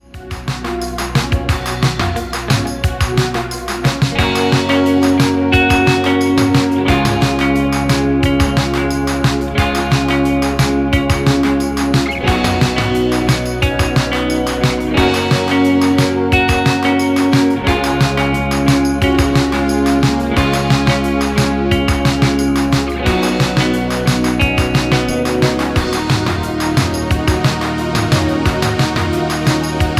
Backing track files: Rock (2136)